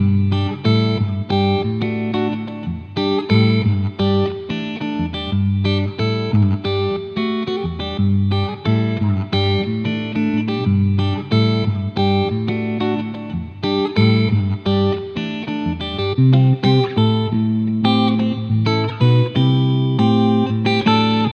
木管乐器
描述：木管乐器。
Tag: 120 bpm Hip Hop Loops Flute Loops 689.46 KB wav Key : D